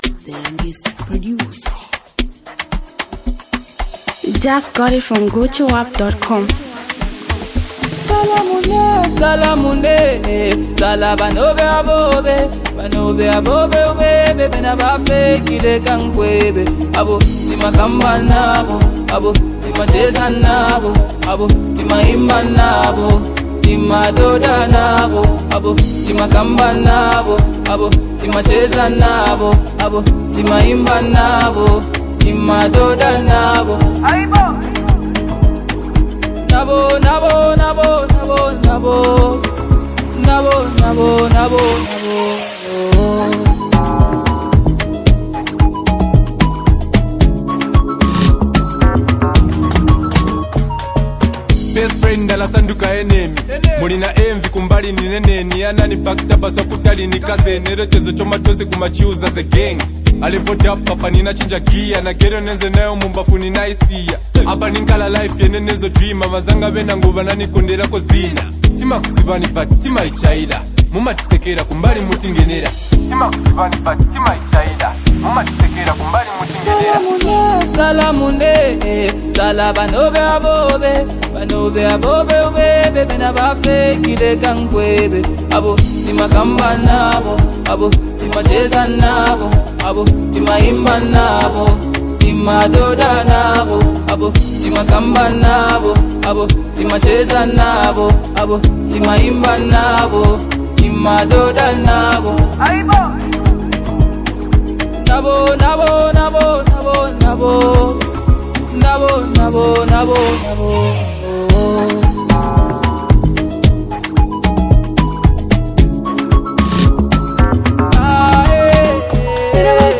Zambian Mp3 Music
street anthem